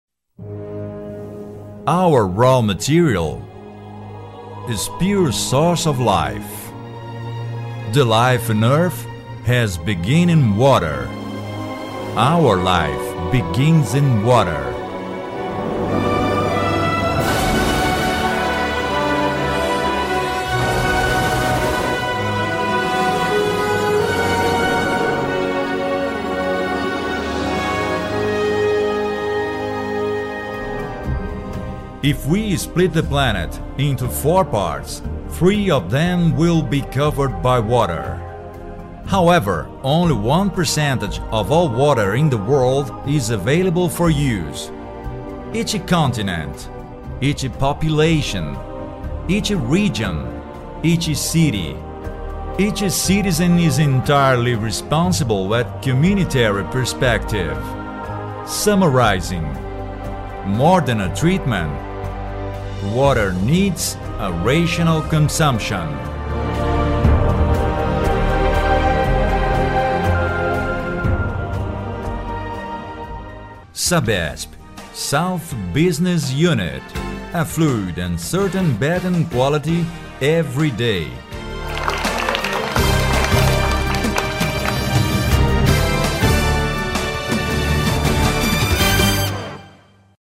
Locução em Inglês para vídeo da Sabesp.